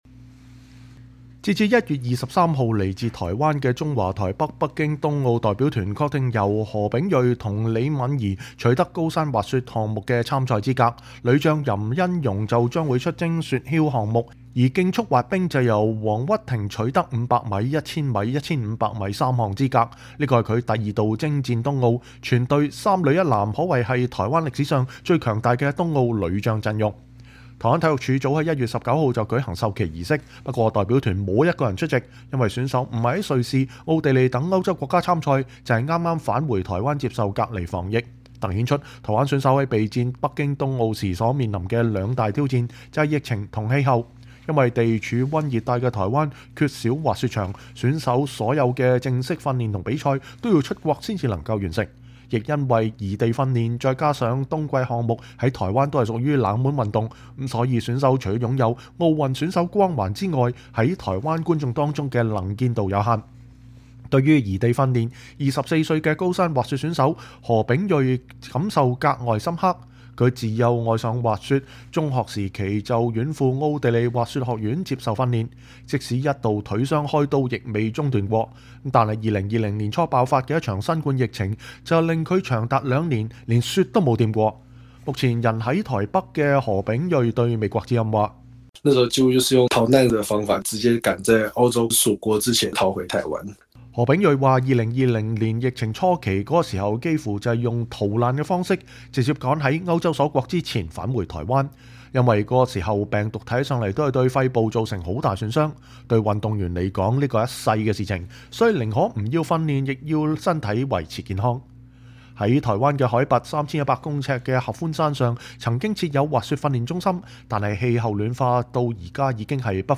出賽前，多位選手接受美國之音採訪，分享了他們在地處溫熱帶的台灣進行冬季項目訓練，所必需克服的許多挑戰。